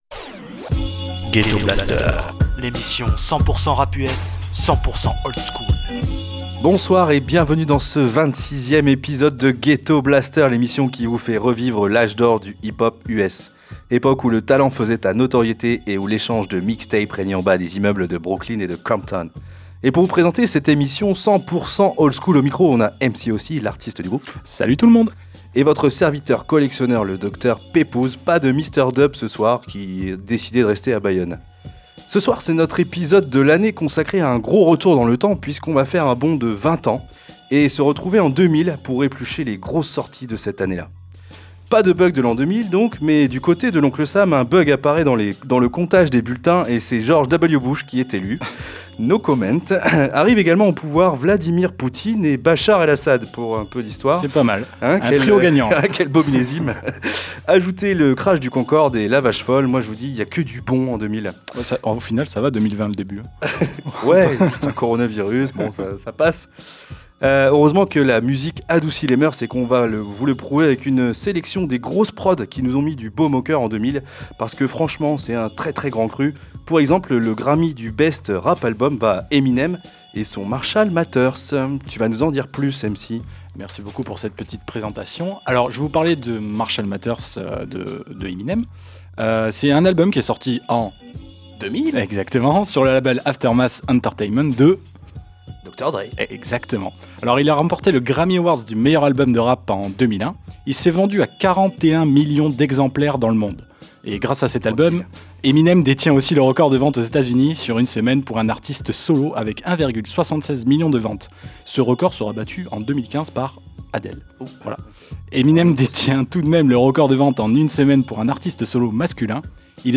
Le hip-hop US des années 80-90